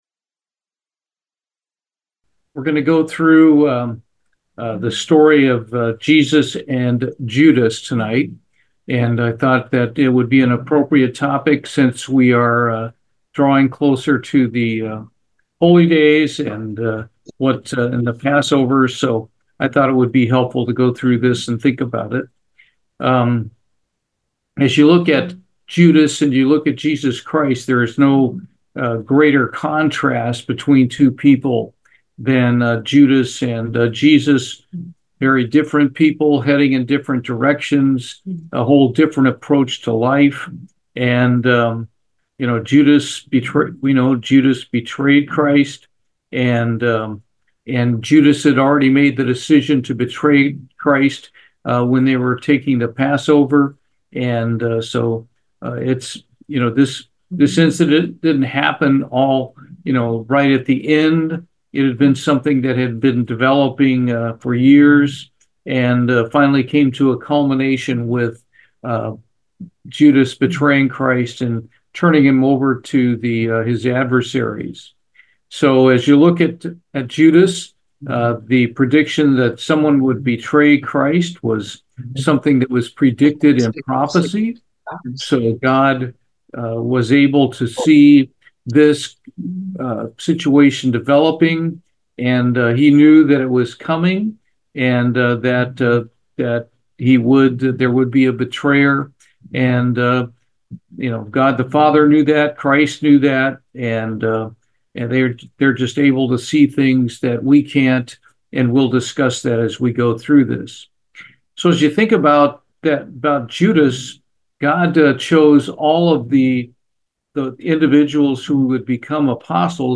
Bible Study, Judas